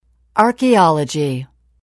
단어번호.0619 대단원 : 3 소단원 : a Chapter : 03a 직업과 사회(Work and Society)-Professions(직업) 출제년도 : 12 archaeology [ɑ̀ːrkiɑ́lədƷi] 명) 고고학 mp3 파일 다운로드 (플레이어바 오른쪽 아이콘( ) 클릭하세요.)
archaeology.mp3